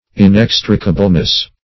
Search Result for " inextricableness" : The Collaborative International Dictionary of English v.0.48: Inextricableness \In*ex"tri*ca*ble*ness\, n. The state of being inextricable.